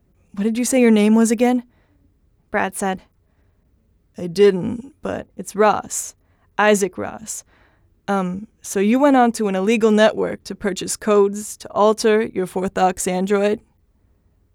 Very quiet muffled talking in background...Can I get rid of it in post?
Special Interest Groups Audiobook Production
It’s a real sound isolation booth, but unfortunately you can still juuust hear it when people are talking loudly.
(Also, I think my last post is still pending mod approval, but here is a better audio sample where you can actually hear a background voice.)